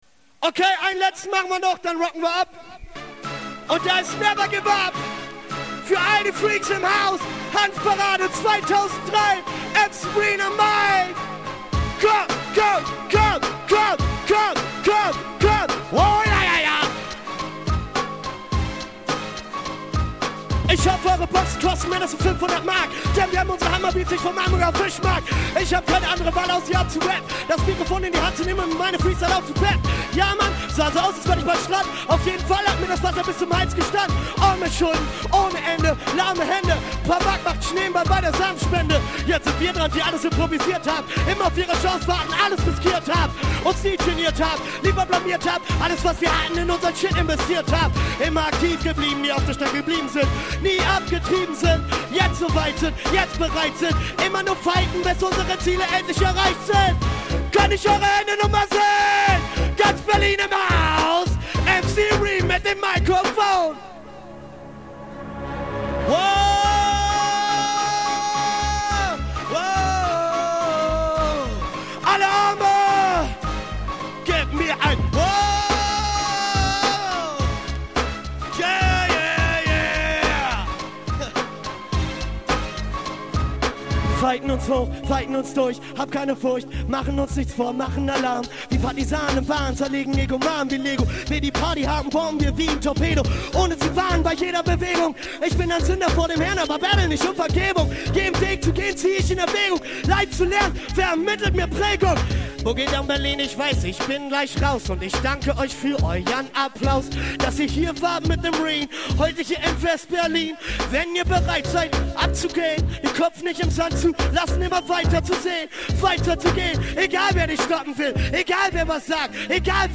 Audio der Hauptbühne
Hip-Hop)Traum von Berlin 1 MBMAGNET